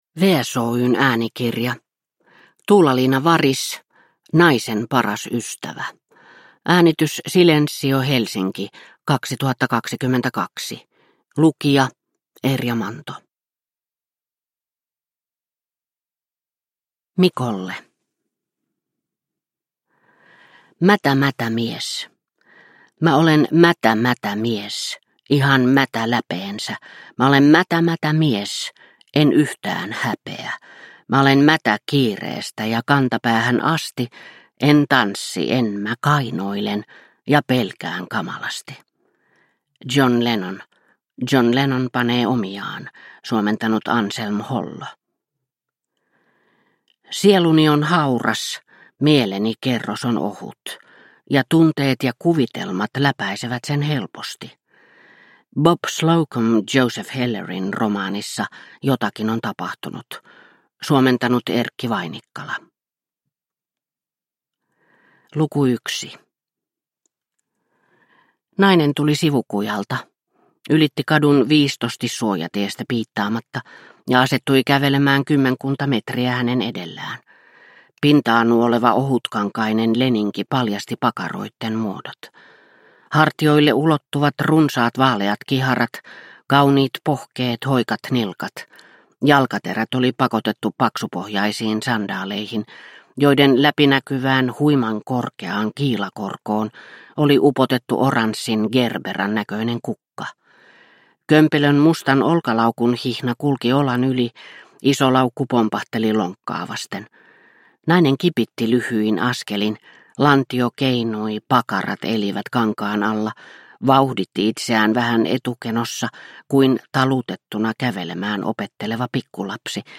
Naisen paras ystävä – Ljudbok – Laddas ner